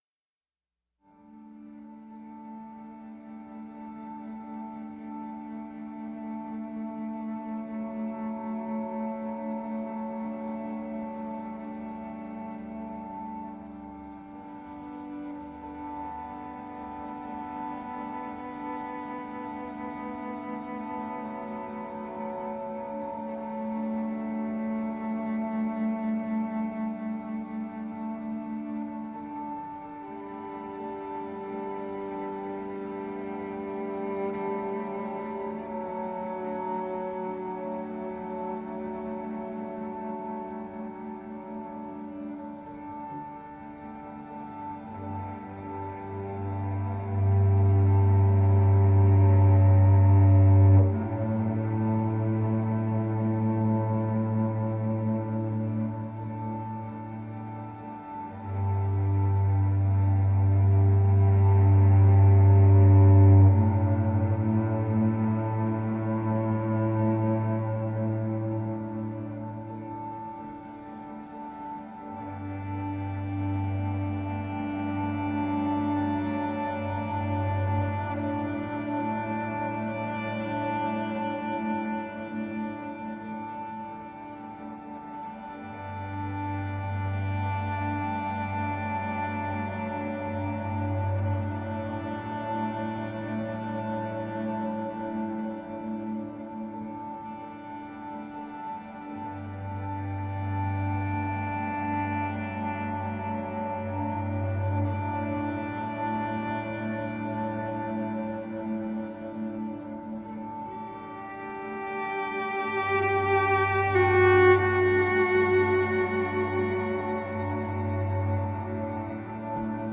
New age meets indian cello.
Tagged as: New Age, Other, Cello, Massage